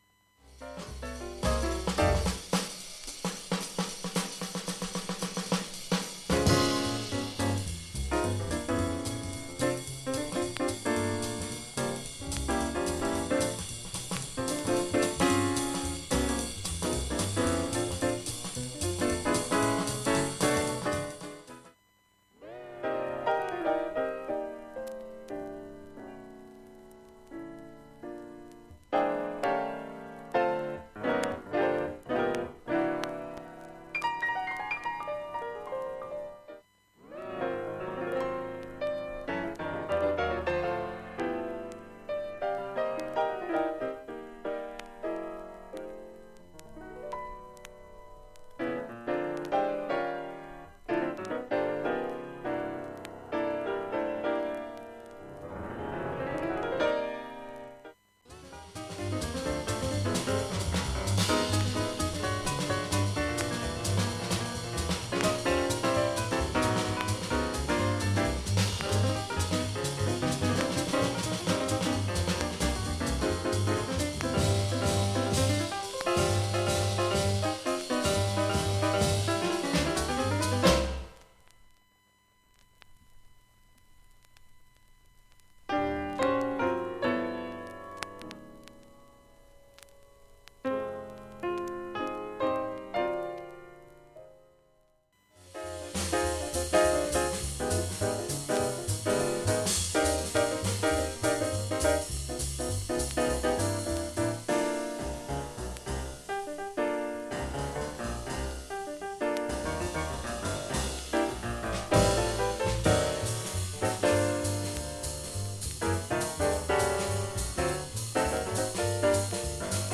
かすかに静かな部などチリ出ますが、
MONO盤(レーベルにINC.表記ありの3rdプレス)